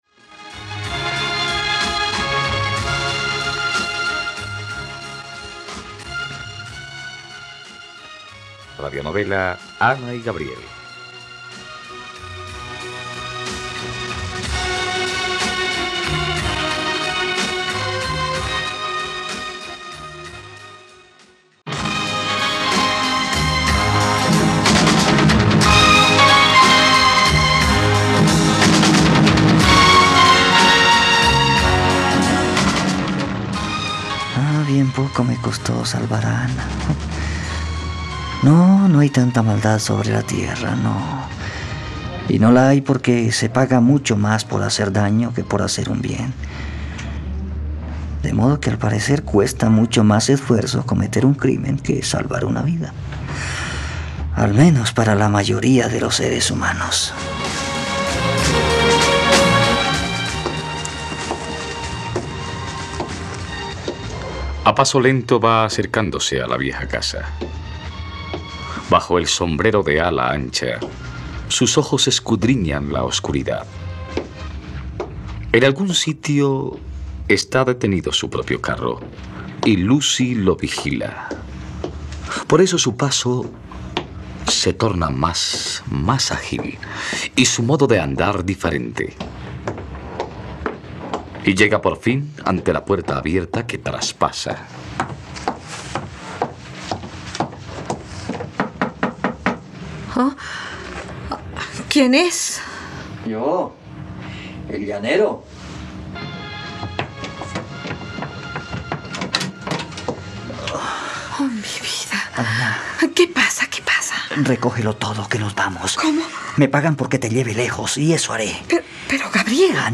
Ana y Gabriel - Radionovela, capítulo 122 | RTVCPlay